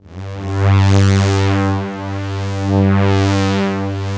110 PAD G2-L.wav